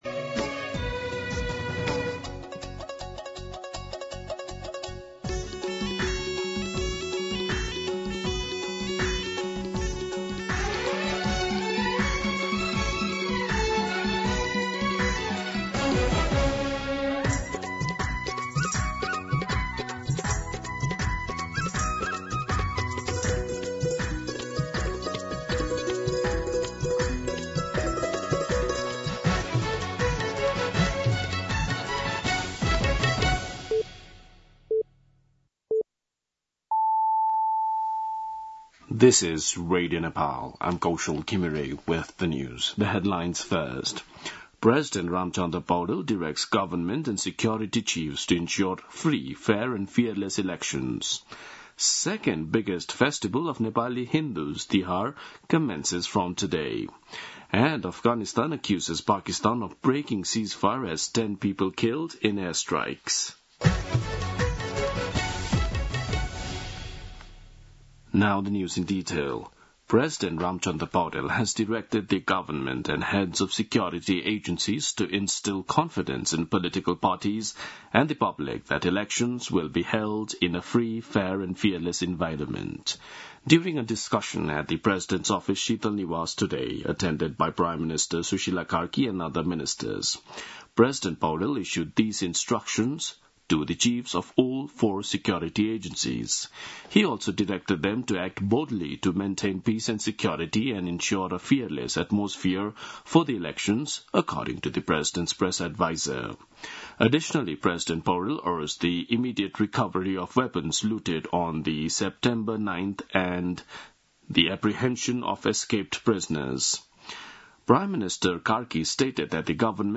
दिउँसो २ बजेको अङ्ग्रेजी समाचार : १ कार्तिक , २०८२
2-pm-English-News-6.mp3